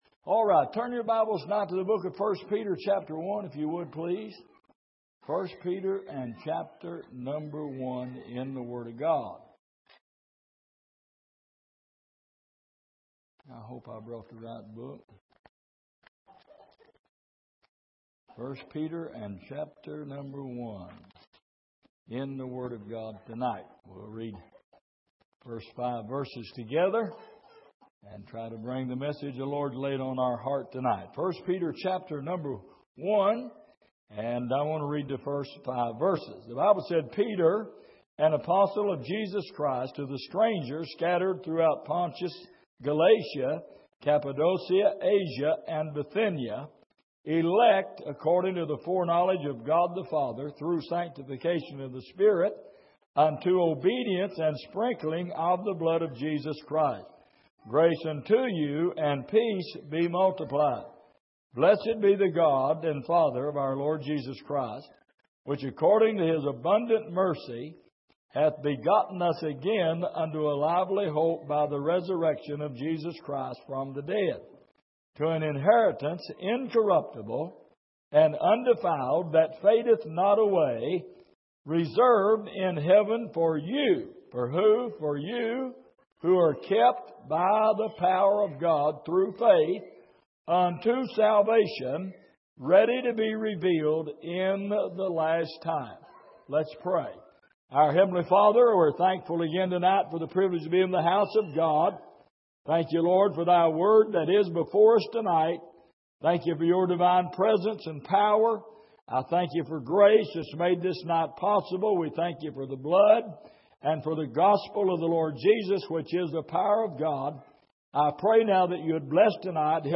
1 Peter 1:1-5 Service: Midweek Keeping Grace « Who Is Tugging On Your Heart?